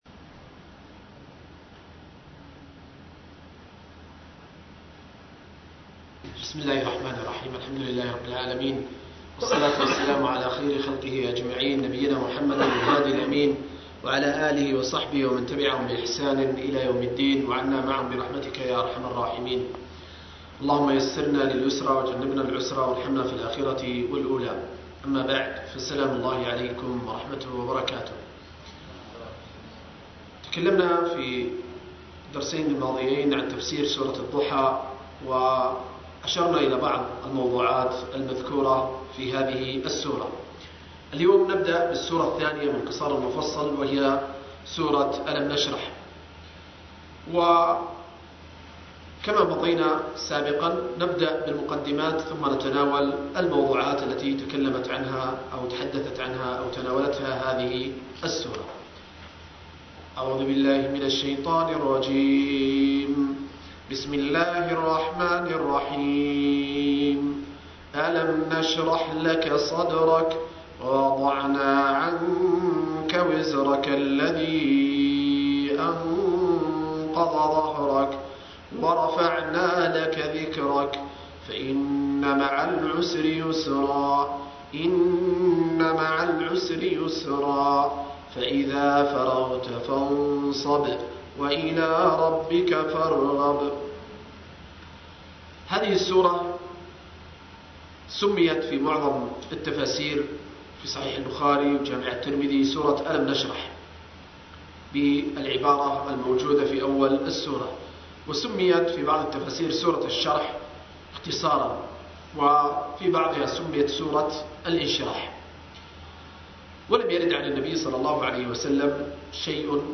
10-التفسير الموضوعي الميسر لقصار المفصل – الدرس العاشر